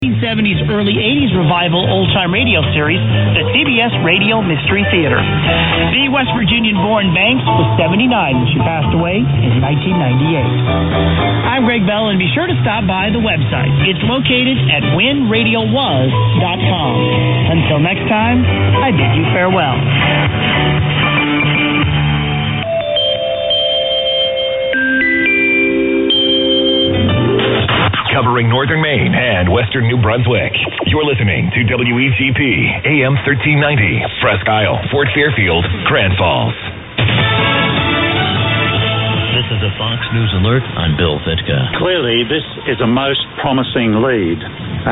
WEGP 1390 - THE TALK OF THE COUNTY - BOOMING:
The other day WEGP was absolutely booming in on 1390 at 0500, proving there is life left in the band yet!! It may as well have been coming from up the road!!
140407_0500_1390_wegp_booming.mp3